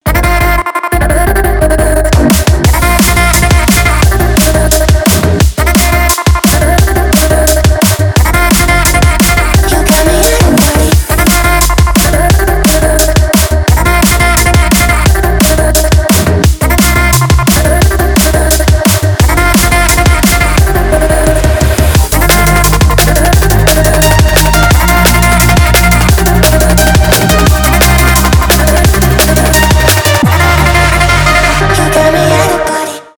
драм энд бейс , клубные